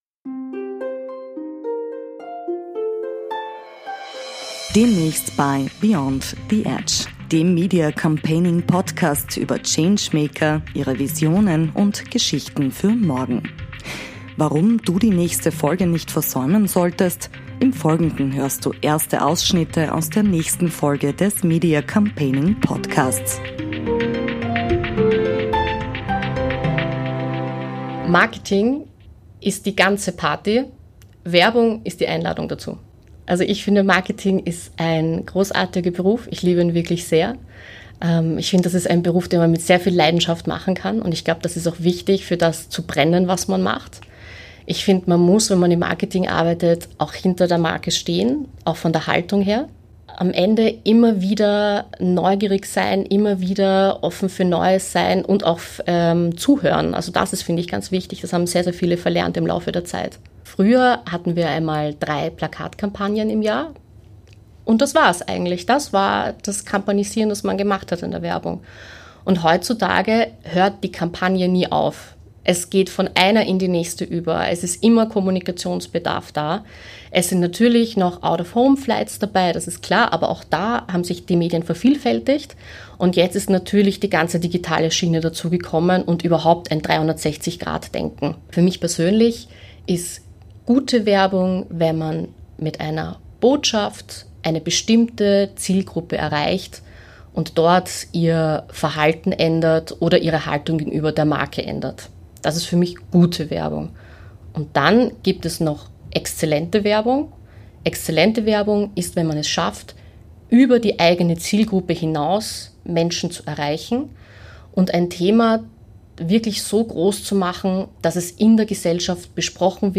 Demnächst auf diesem Kanal gibt es ein außergewöhnliches Gespräch